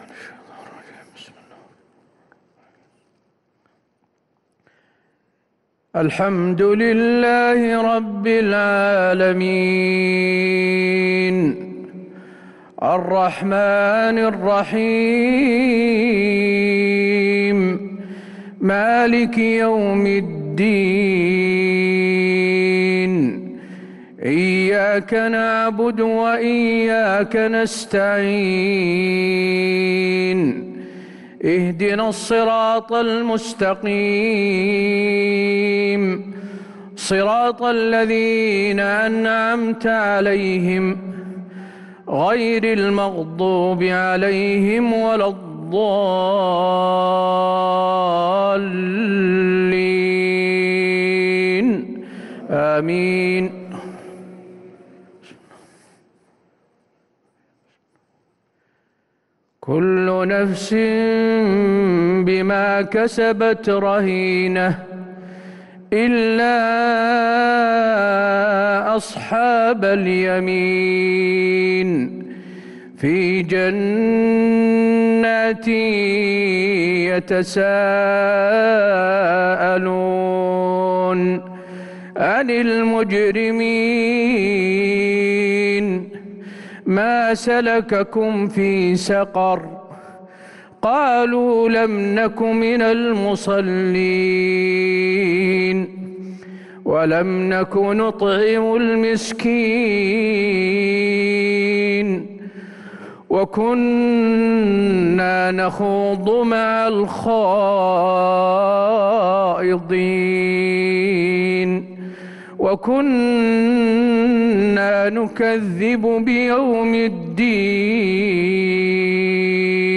صلاة العشاء 1-8-1443هـ خواتيم سورتي المدثر 38-56 و الإنفطار 13-19 | Isha 4-3-2022 prayer Surah Al-Mudathir and AlInfitar > 1443 🕌 > الفروض - تلاوات الحرمين